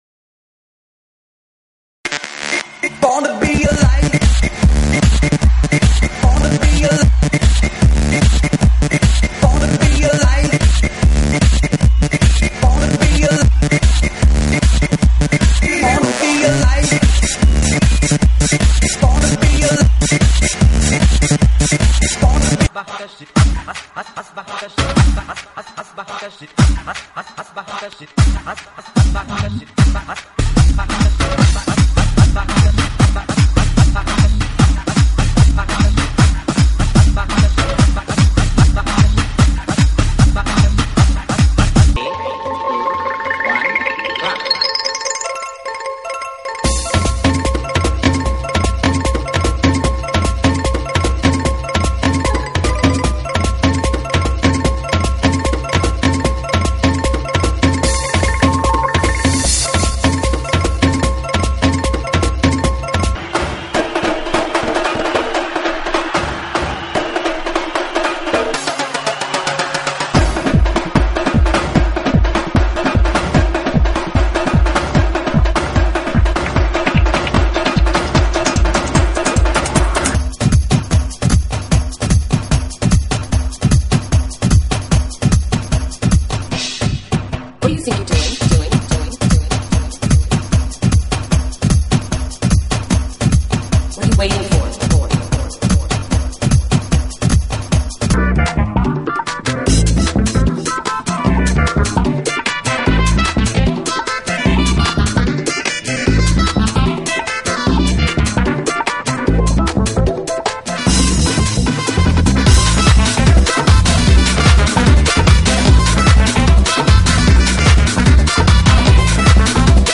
GENERO: DANCE – ELECTRONICA